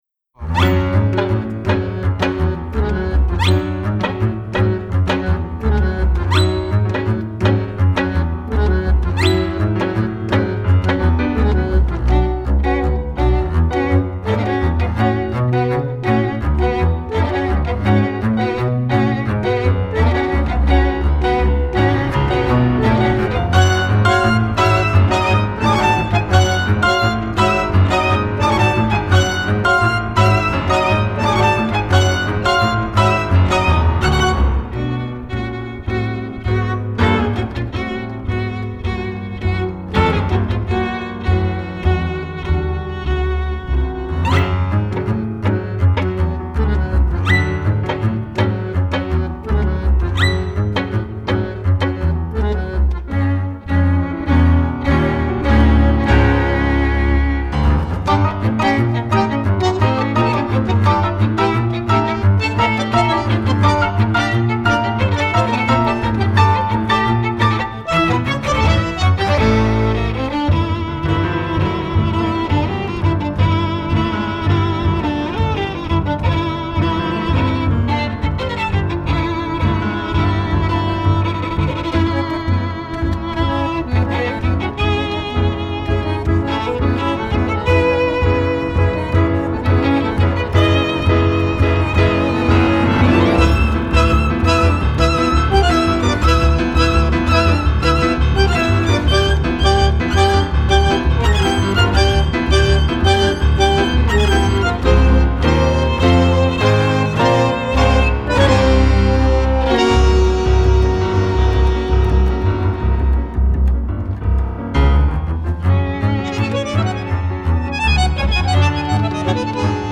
Genre: Jazz.